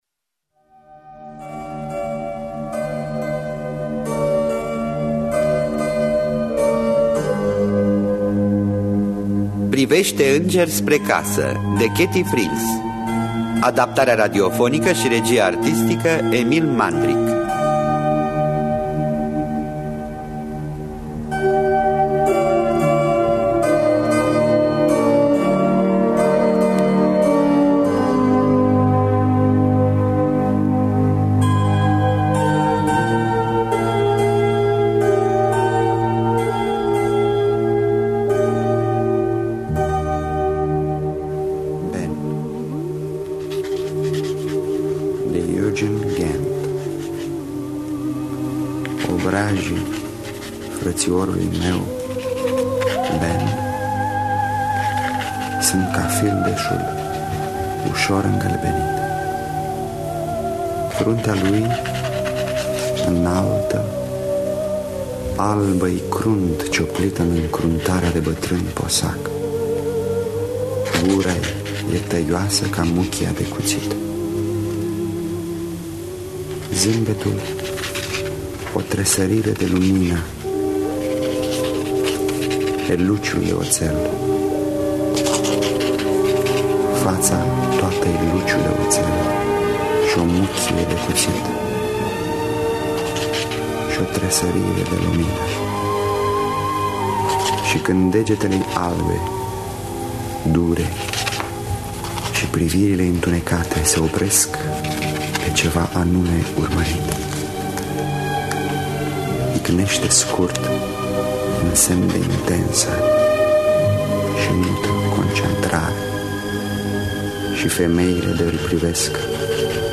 Priveşte înger spre casă de Ketty Frings – Teatru Radiofonic Online
Adaptarea radiofonică şi regia artistică